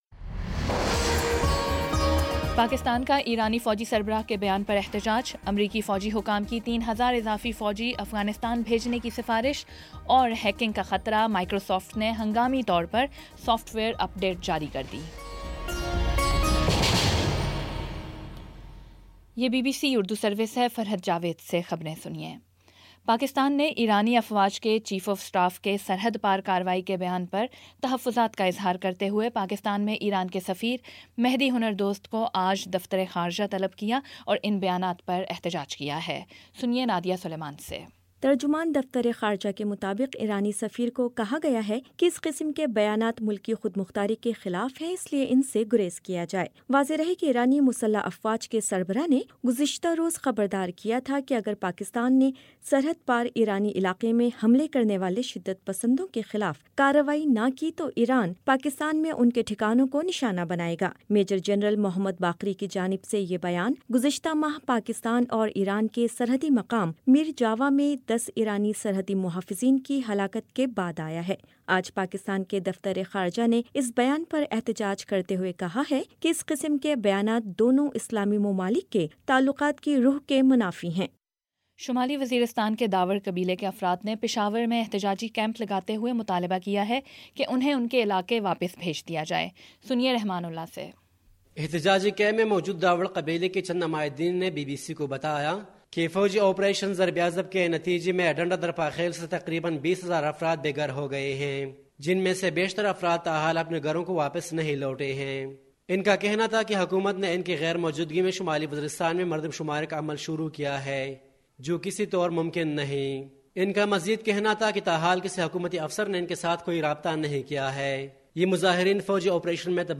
مئی 09 : شام چھ بجے کا نیوز بُلیٹن